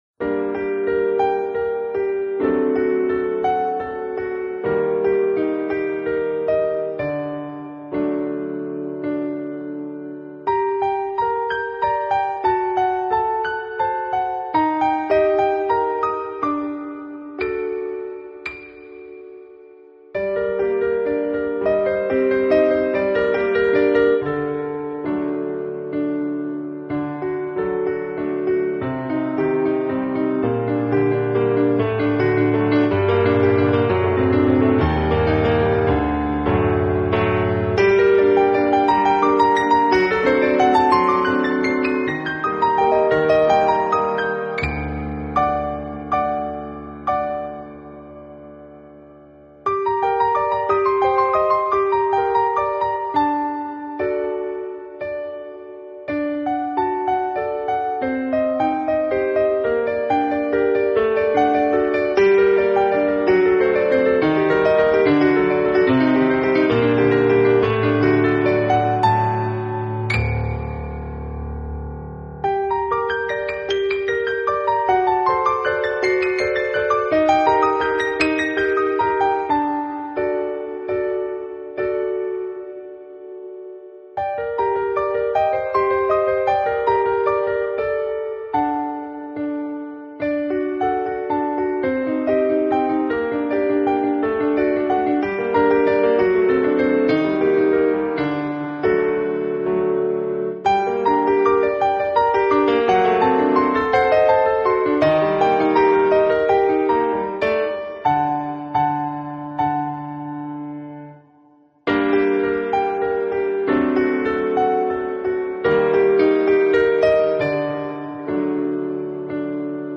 音乐类型：New Age/piano solo
音乐如水滴般清澈透明宁静
冰凉，又象天使般可爱灵动，很女性的音乐很女性的心境。